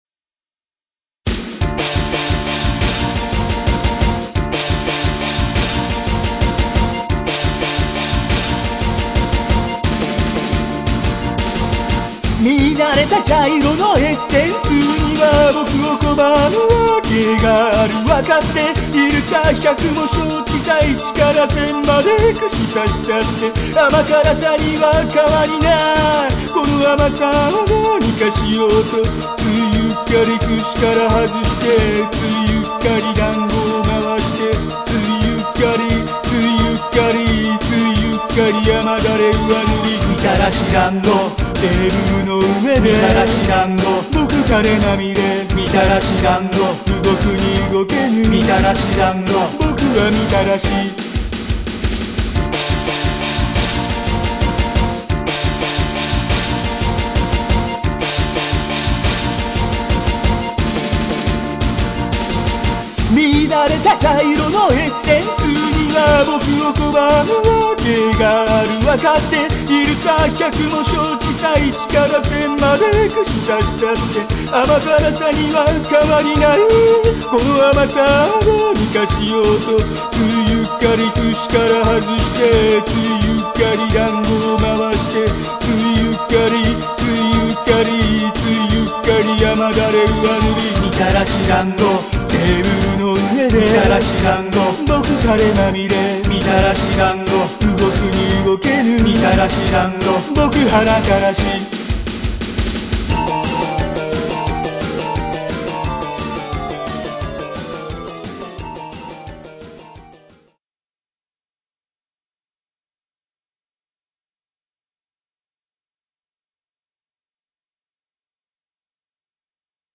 皆の衆、この甘辛くも切ないソウルフルな歌声を心して聴くがよい！
ちなみにオケはコチラのを拝借。
替え歌 トラックバック_ コメント_0